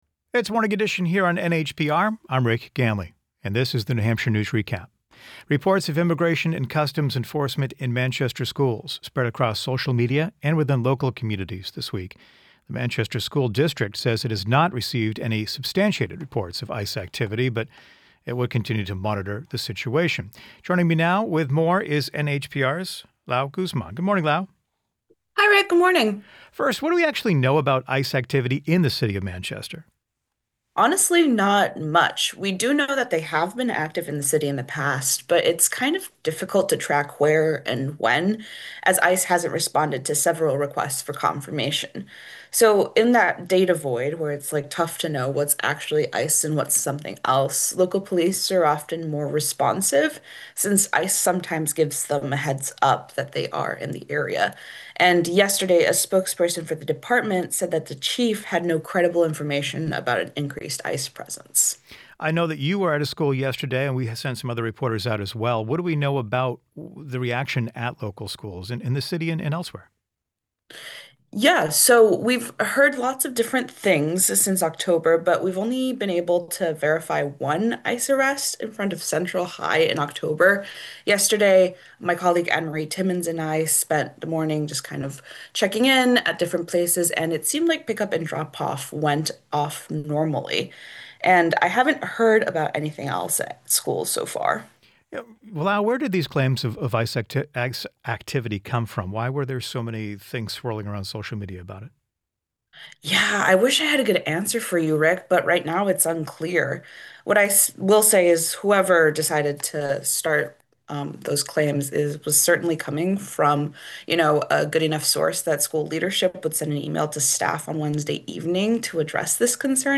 Be a guest on this podcast Language: en-us Genres: Daily News , News Contact email: Get it Feed URL: Get it iTunes ID: Get it Get all podcast data Listen Now...